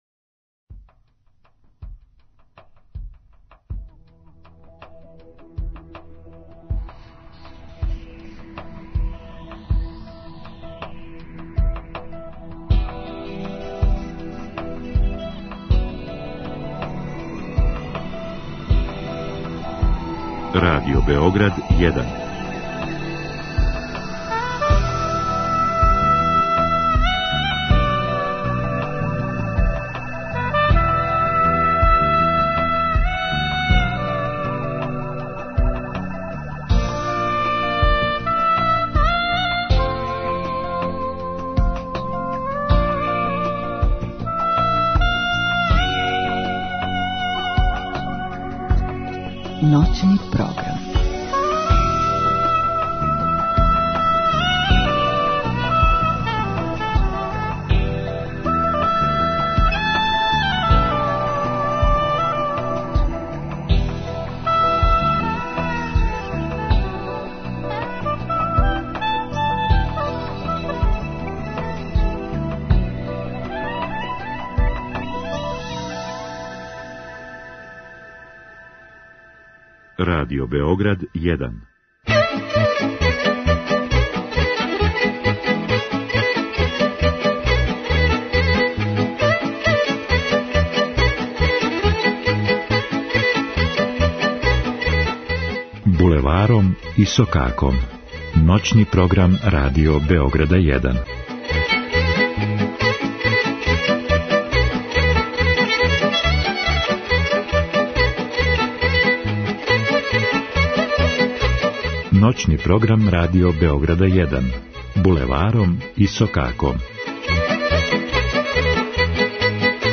Уживо из студија РБ 1 емитоваћемо традиционалну, староградску и компоновану музику у народном духу. Током новог серијала уврстићемо и госте, као и нове рубрике.